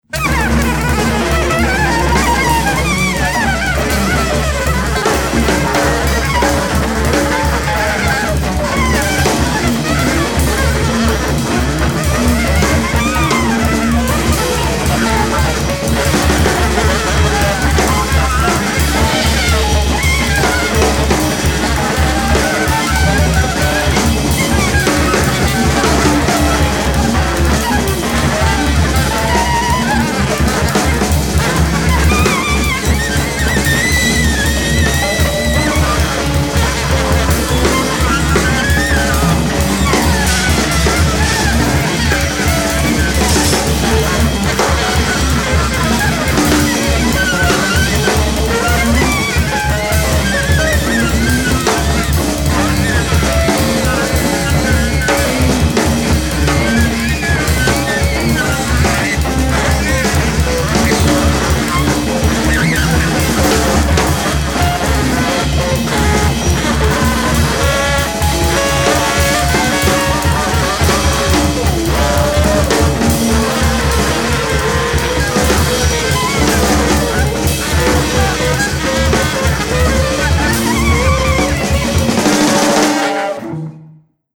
alto sax, trumpet and percussion
tenor sax and guitar
drums and percussion